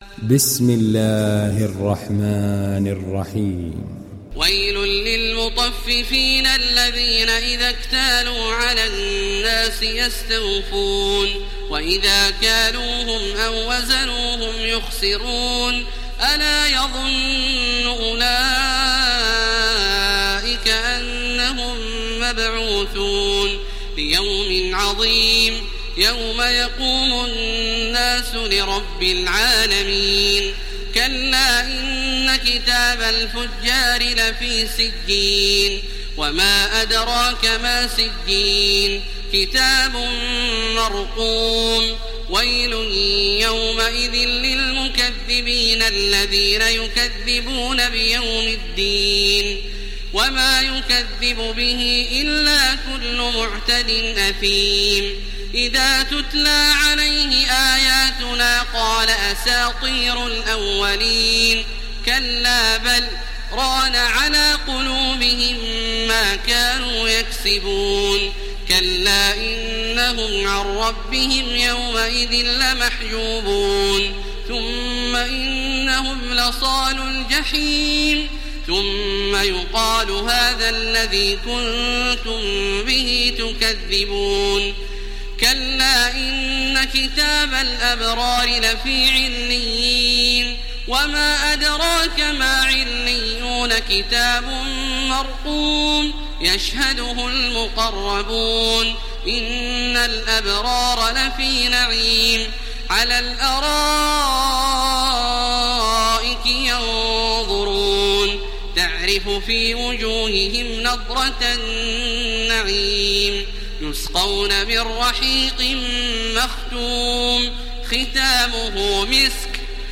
ডাউনলোড সূরা আল-মুতাফফিফীন Taraweeh Makkah 1430